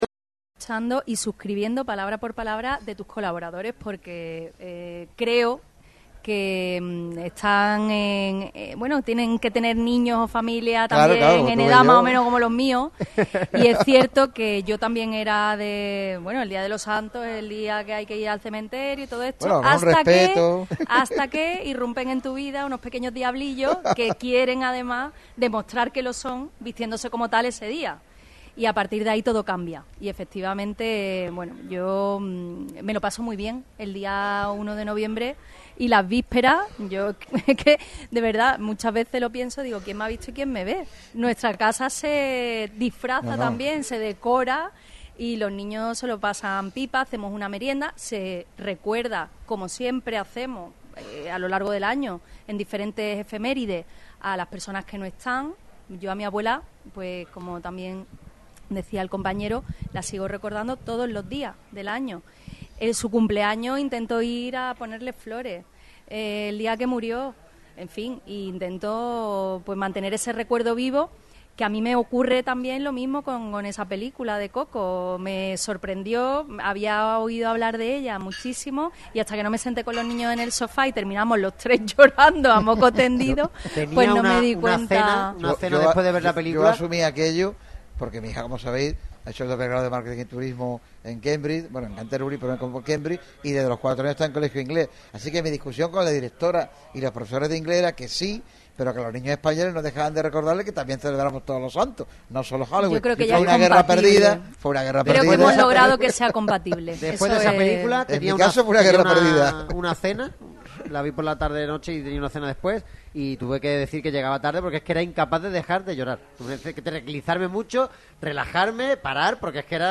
Este jueves ha pasado por el micrófono rojo Patricia Navarro, delegada del Gobierno de la Junta de Andalucía y presidenta del PP en toda la provincia en el programa especial desde Los Mellizos en Calle Sánchez de Lara por sus jornadas 15 Días de Oro.